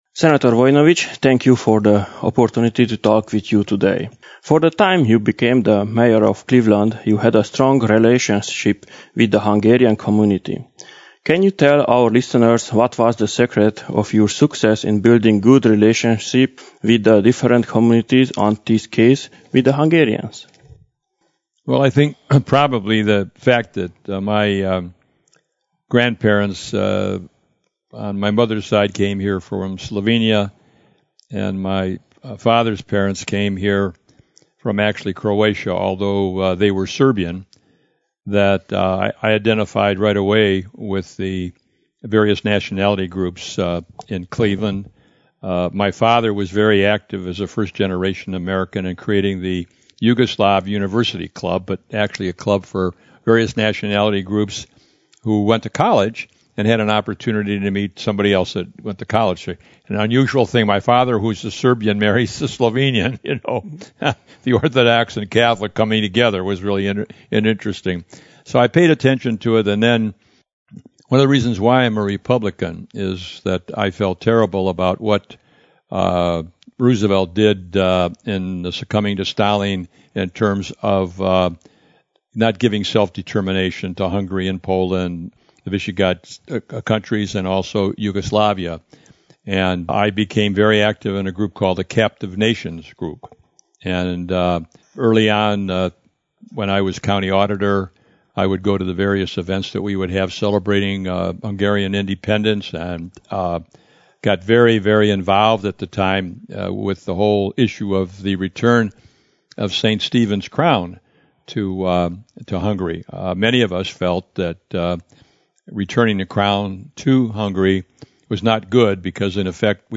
A következőkben hallgassák meg ezt az interjút, emlékezve George Voinovich munkásságára, amit tett a magyarságért itt Clevelandben, egész Amerikában és Magyarországon is.